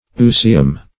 Search Result for " ooecium" : The Collaborative International Dictionary of English v.0.48: Ooecium \O*oe"ci*um\, n.; pl.